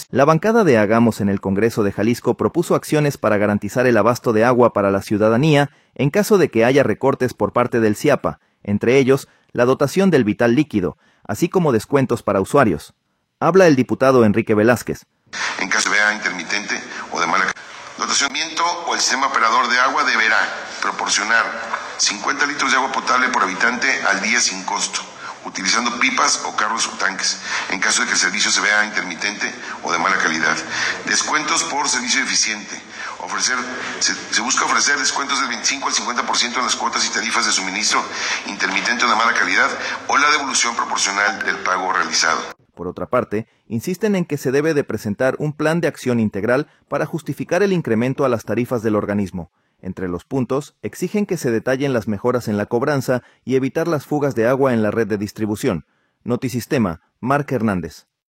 Habla el diputado Enrique Velázquez.